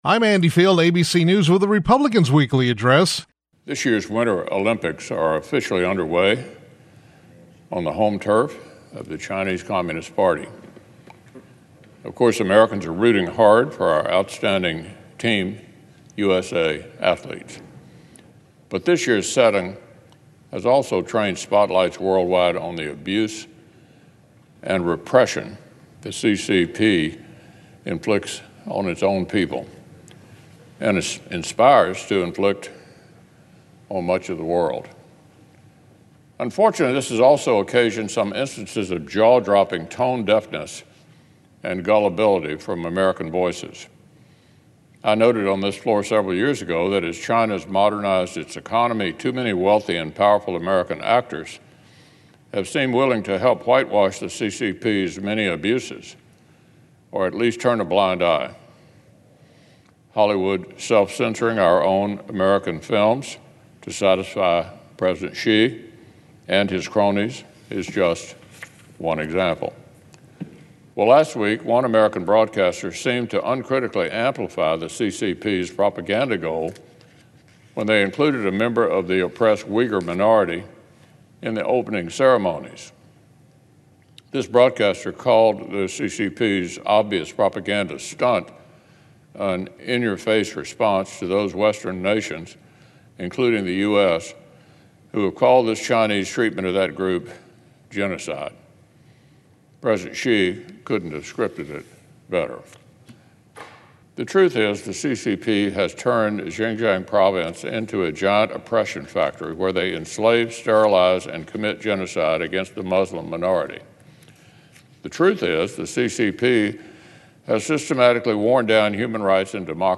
U.S. Senate Republican Leader Mitch McConnell (R-KY) delivered remarks yesterday on the Senate floor regarding China.